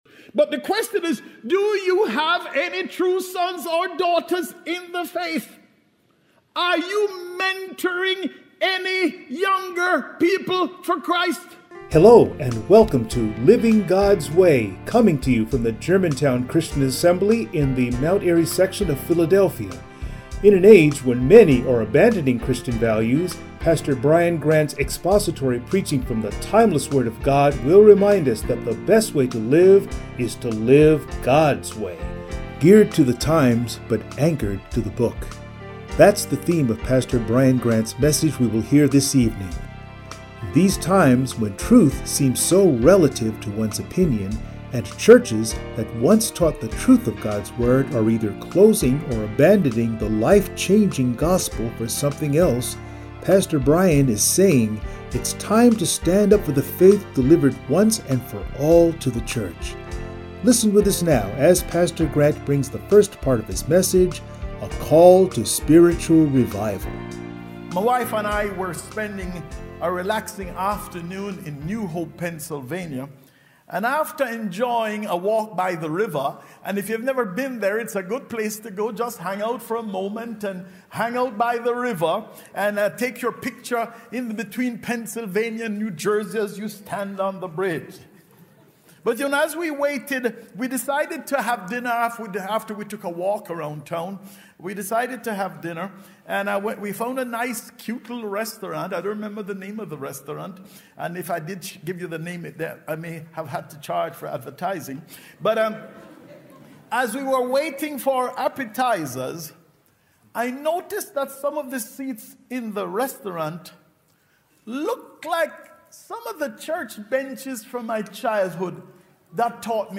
Passage: 1 Timothy 1:1-15 Service Type: Sunday Morning